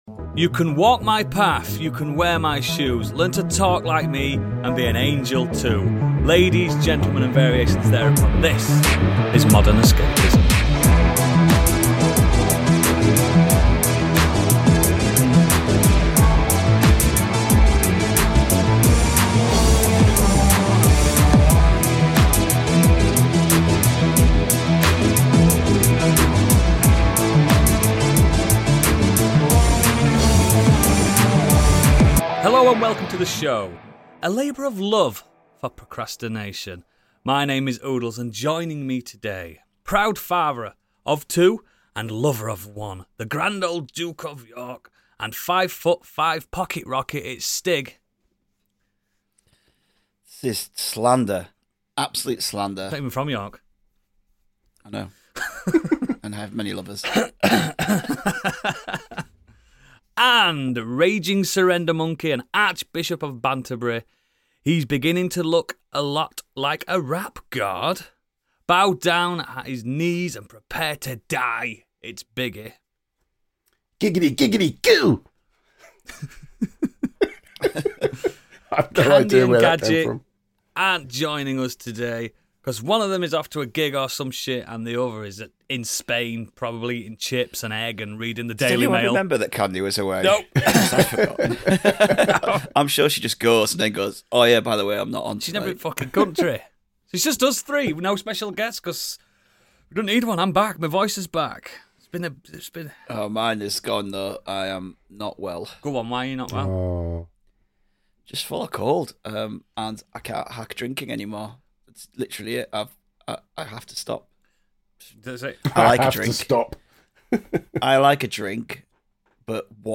A weekly podcast where five friends talk pop culture from TV, Games, Movies, Books, Music, Wrestling, Comics, Podcasts and everything in between. Each week we discuss the news from the pop culture & media world as well as reviewing what media we've been consuming that week.